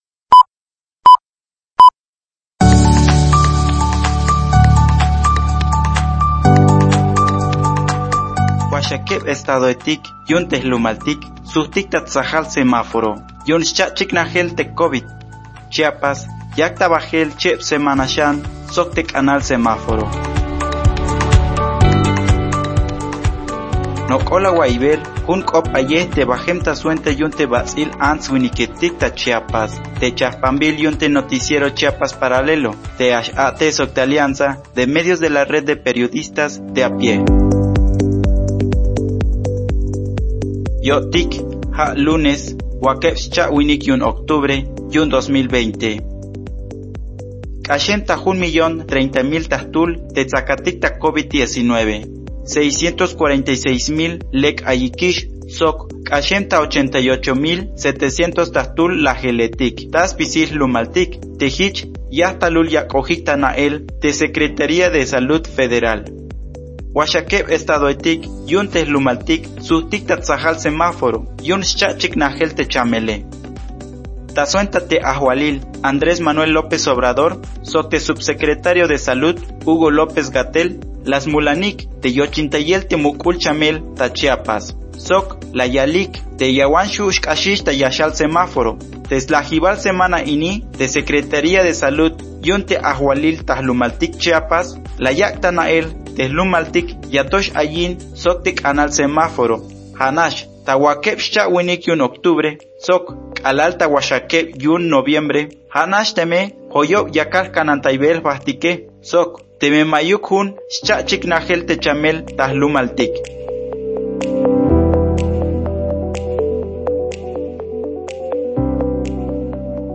Resumen informativo COVID-19